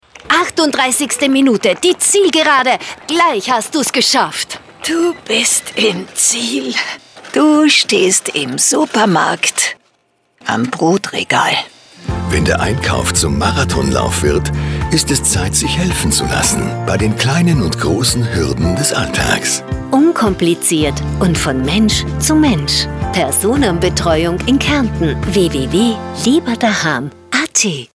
Unsere Radiospots